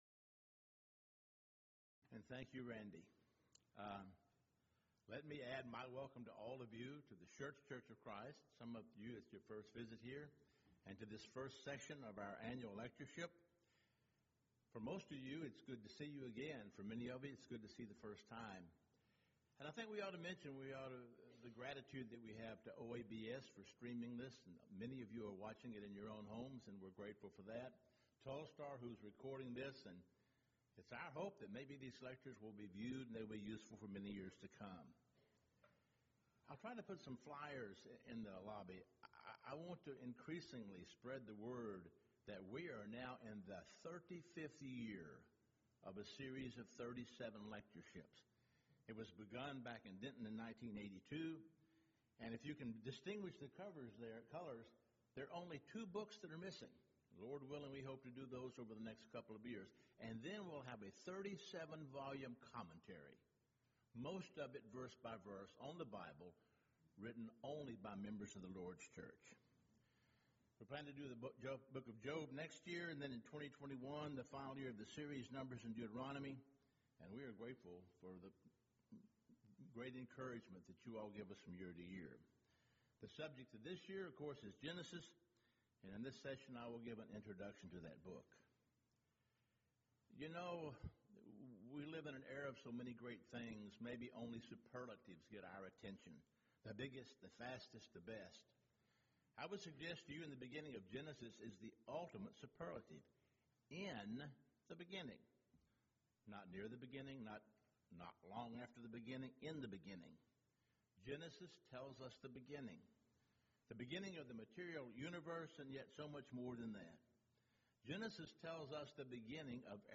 Event: 16th Annual Schertz Lectures
lecture